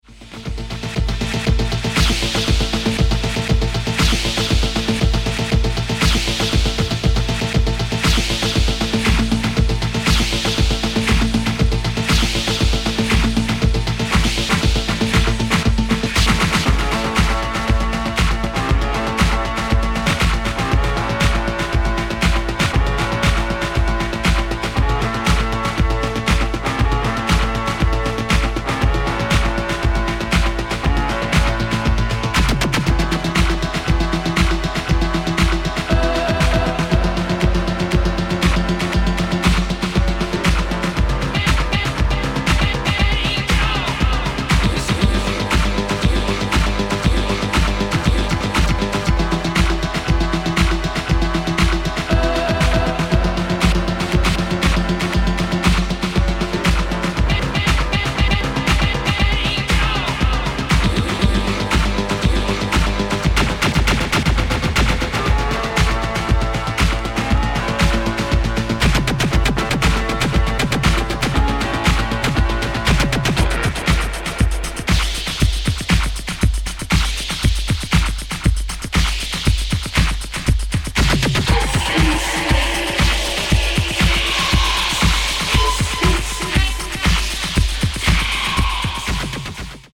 Disco Electro